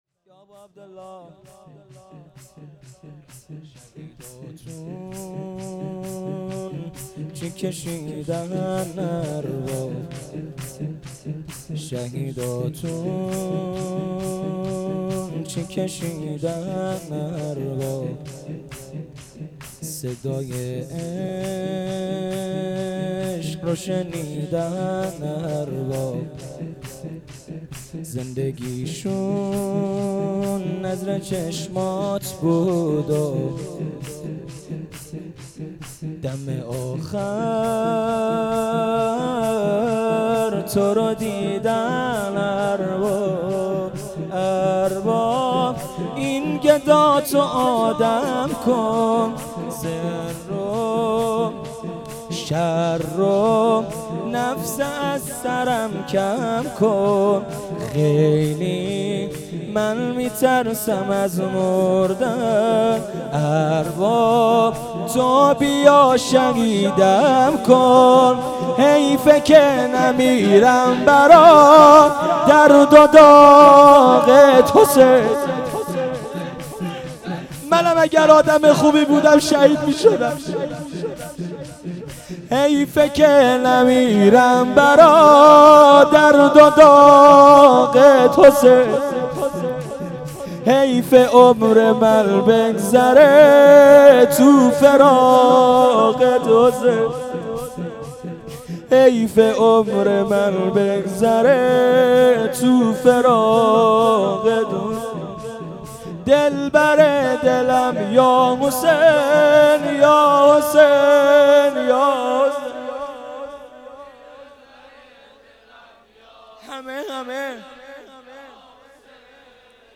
شور
شب هفتم محرم 1398